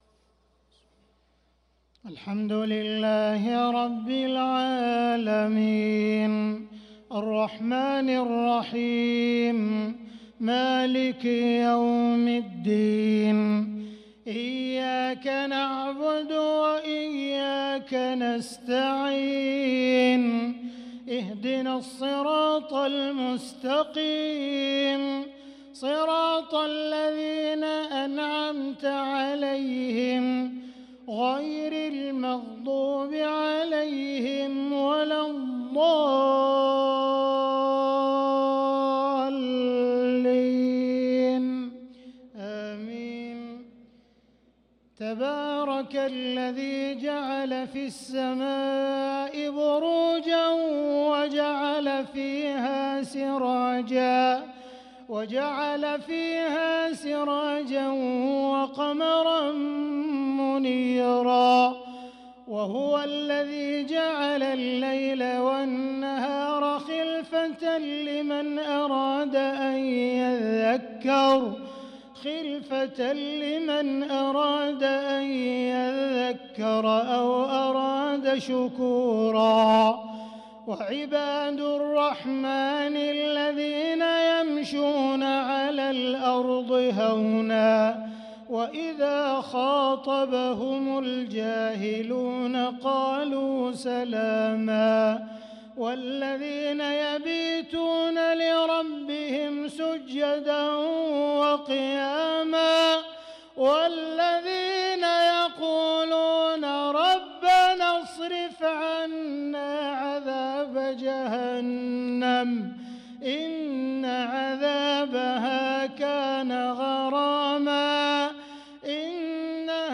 صلاة العشاء للقارئ عبدالرحمن السديس 21 رجب 1445 هـ